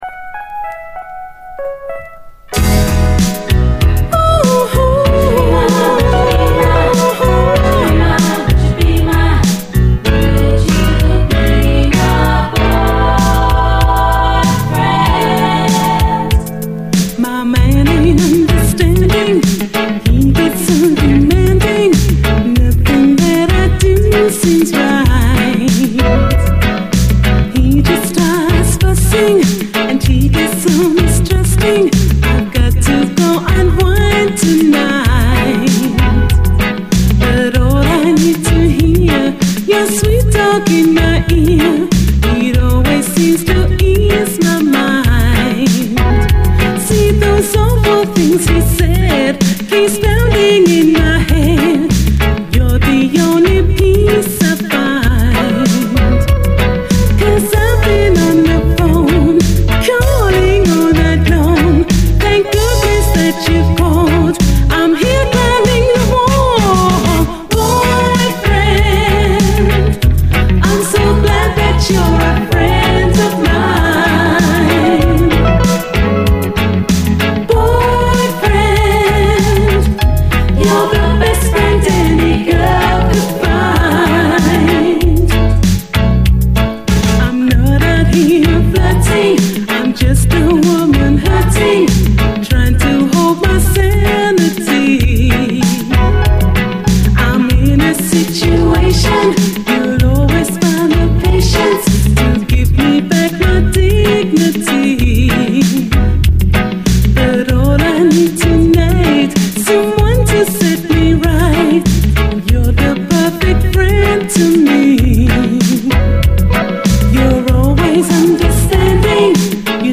REGGAE
爽快なイントロからハートを鷲掴みにされる、キャッチー＆ポップなキラー・ガーリー・ラヴァーズ！
ダビーなインスト・ヴァージョンもまたスムース＆ビューティフル！